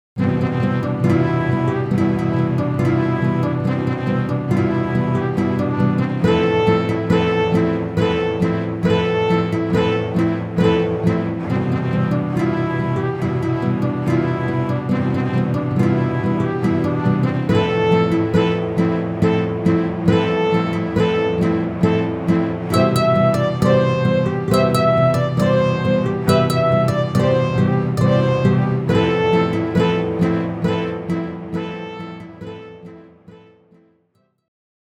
Documentary Soundtrack
Based on Folk Music of the Andes
Orchestral Album